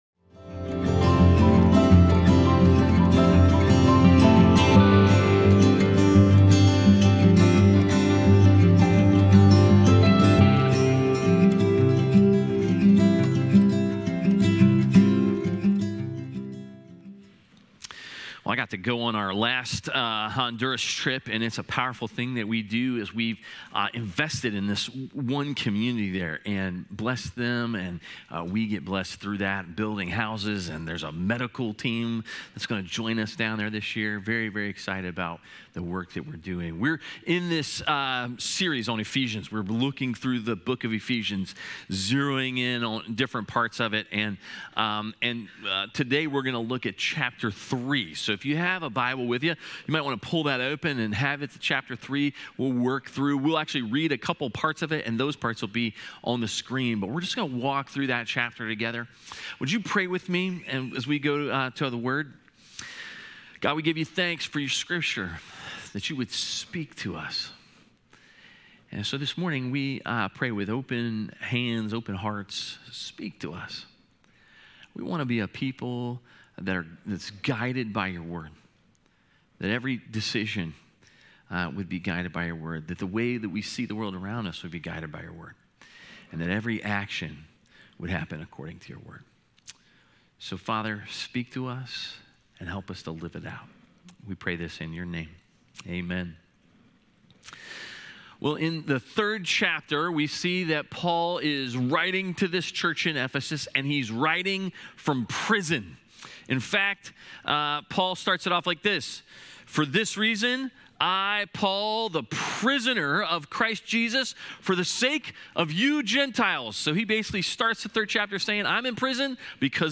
A message from the series "The Book of Ephesians."